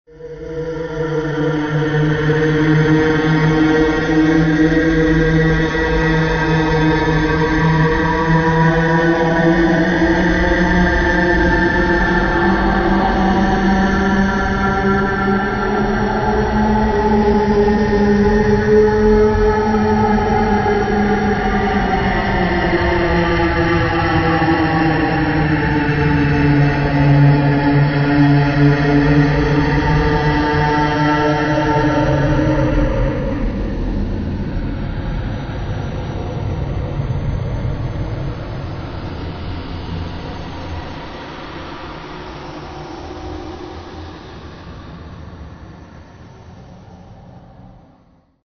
На этой странице собраны звуки души — необычные аудиокомпозиции, отражающие тонкие эмоциональные состояния.